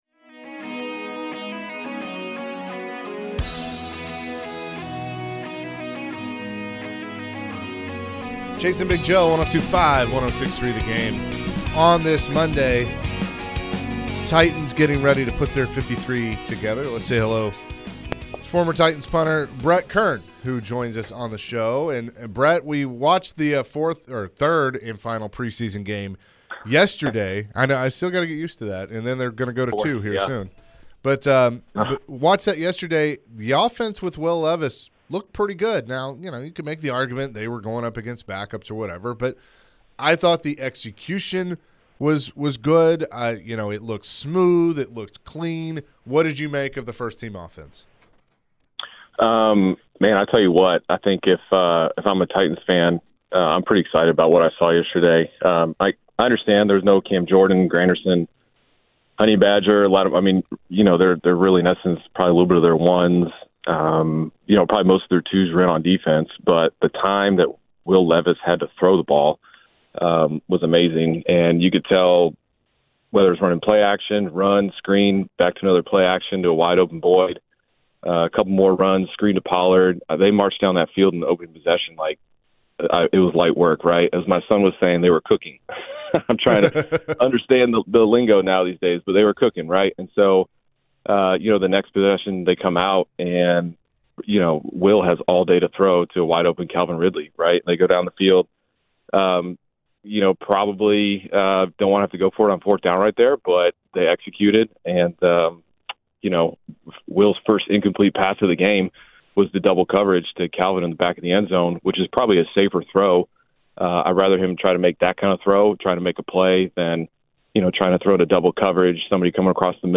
Former Tennessee Titans Punter Brett Kern joined the show and shared his reaction to the Titans 30-27. Later in the conversation, Brett was asked about roster cut day and how the season might go for the 24-25 Season, plus roster cuts